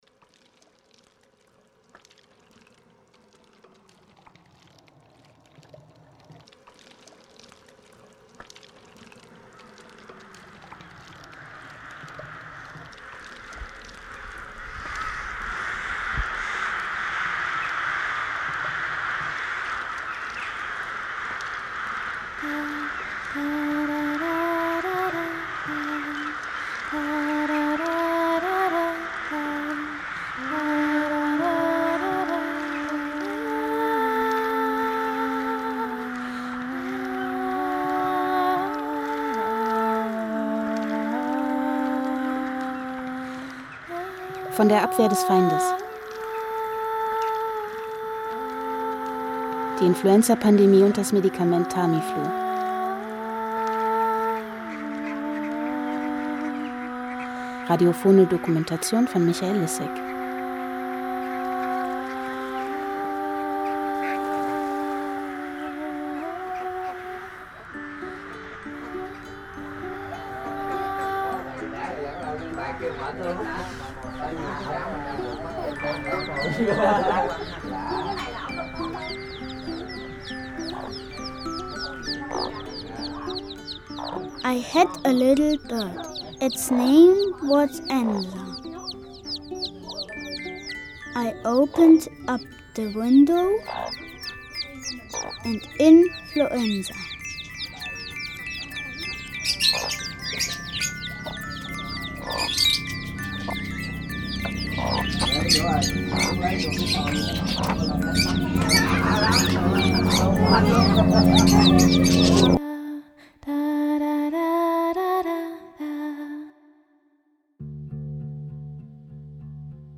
radiofeature